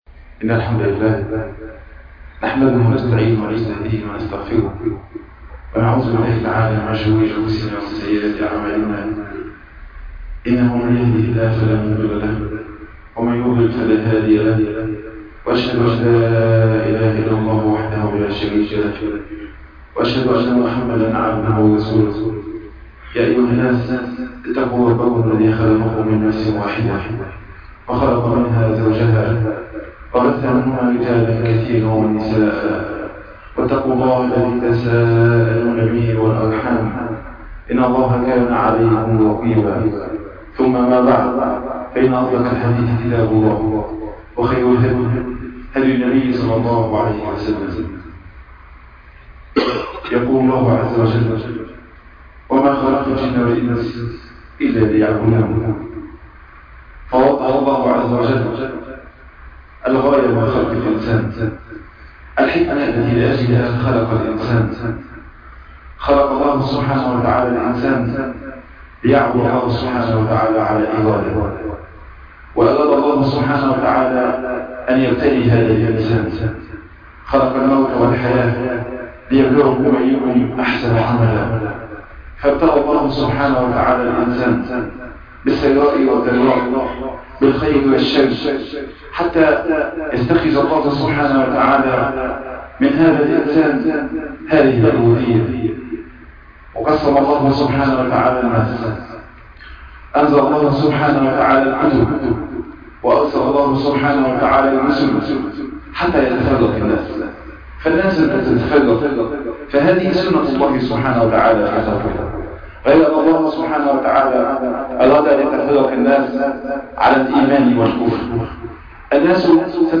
عنوان المادة أبو عبيدة بن الجراح- خطب الجمعة تاريخ التحميل السبت 11 يوليو 2020 مـ حجم المادة 16.06 ميجا بايت عدد الزيارات 276 زيارة عدد مرات الحفظ 115 مرة إستماع المادة حفظ المادة اضف تعليقك أرسل لصديق